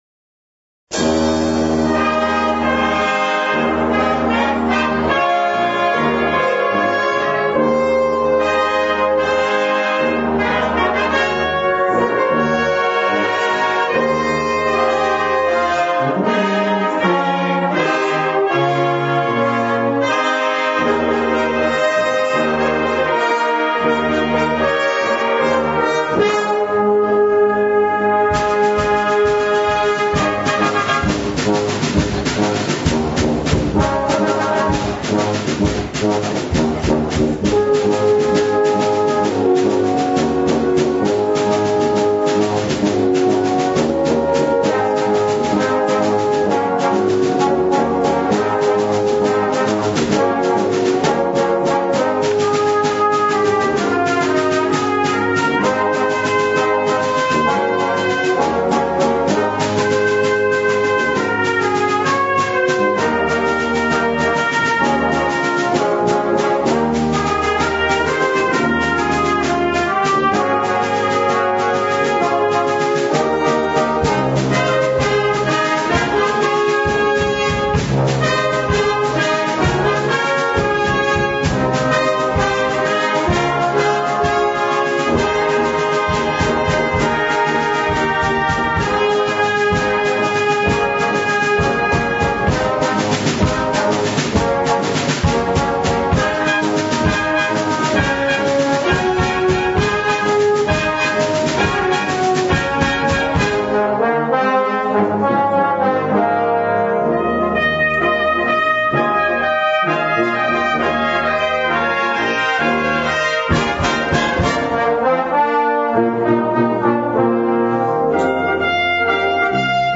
Brass Concert with Vocal Solos